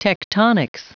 Prononciation du mot tectonics en anglais (fichier audio)
Prononciation du mot : tectonics